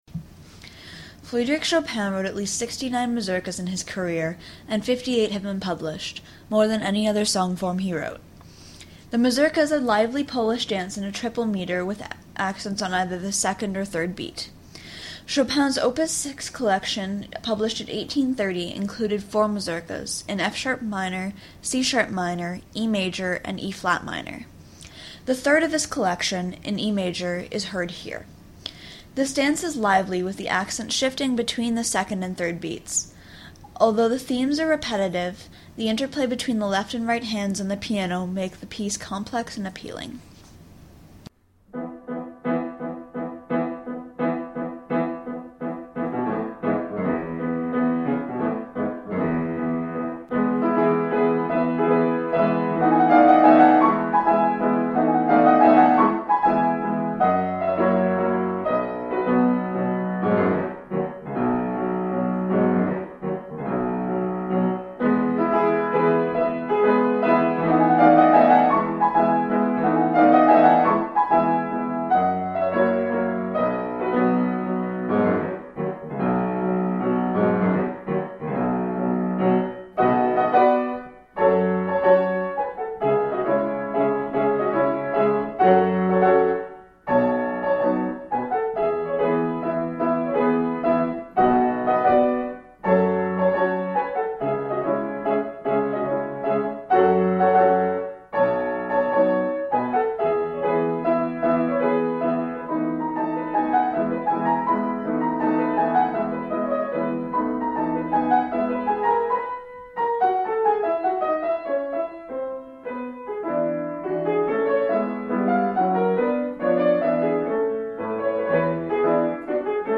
To listen to this audio please consider upgrading to a web browser that supports HTML5 audio This is an excerpt from Polish French composer, Frédéric Chopin's Mazurkas in E major, Op. 6.